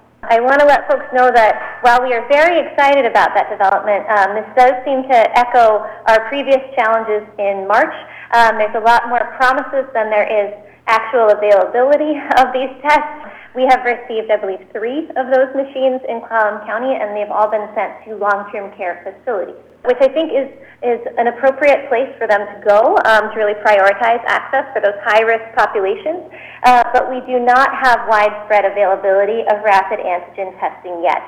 PORT ANGELES – At Friday morning’s Covid-19 briefing, Health Officer Dr. Allison Berry Unthank first updated the numbers.